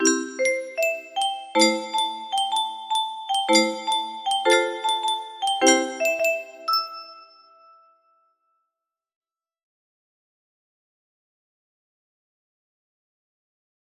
M13-M17 music box melody